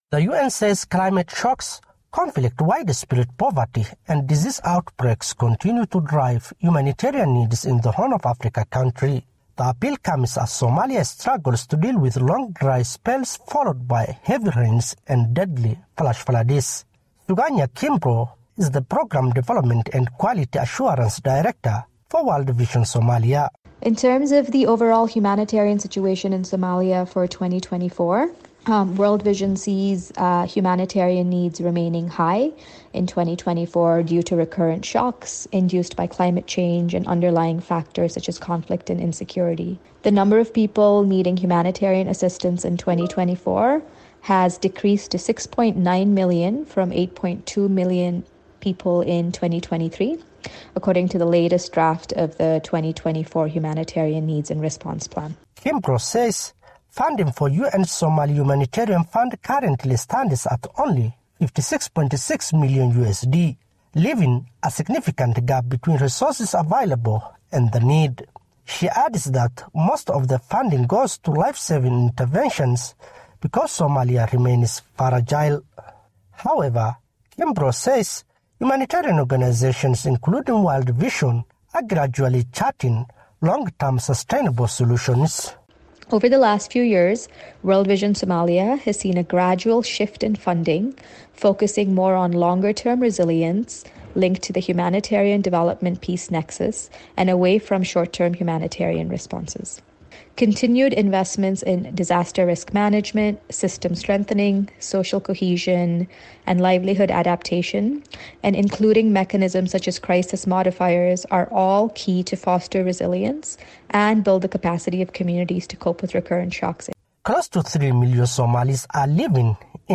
reports from Mogadishu